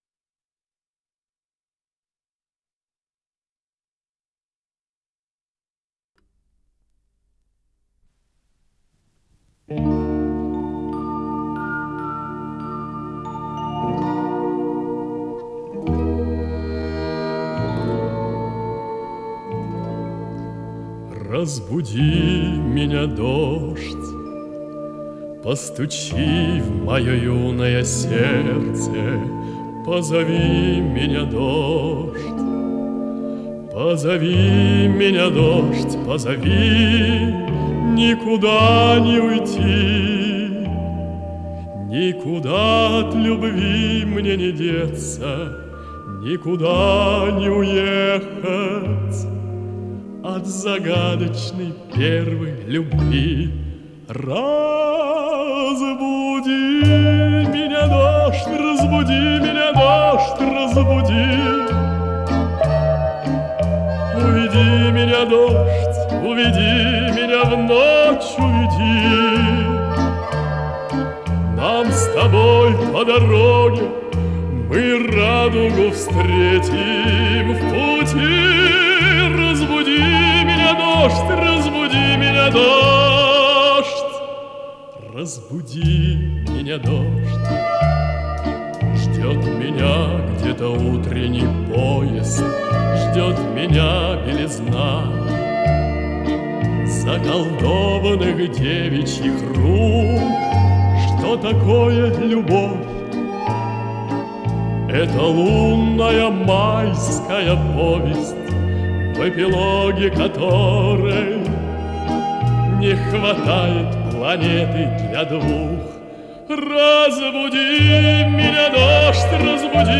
с профессиональной магнитной ленты
баритон
ВариантДубль моно